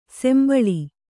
♪ sembaḷi